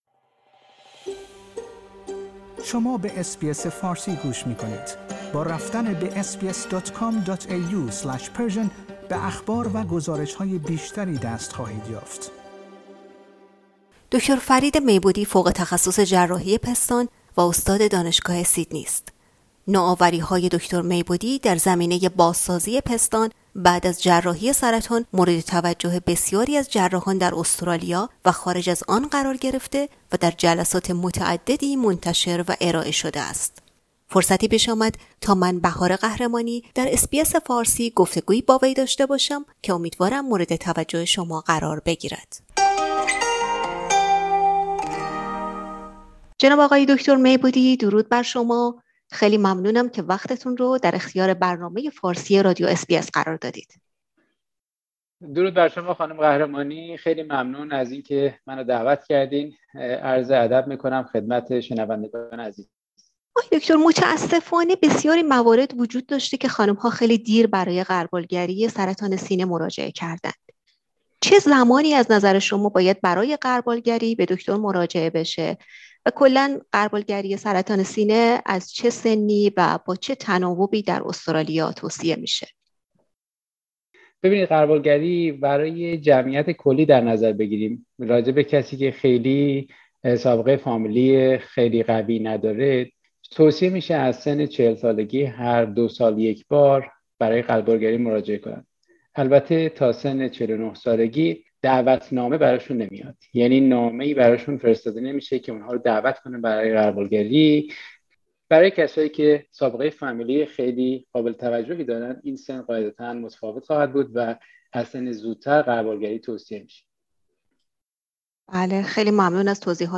در این گفتگو به طور اجمالی به موارد زیر پرداخته می شود: